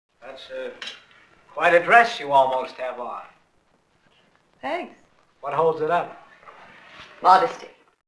My favorite funny moviescenes on wav file!